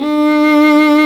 Index of /90_sSampleCDs/Roland - String Master Series/STR_Viola Solo/STR_Vla2 _ marc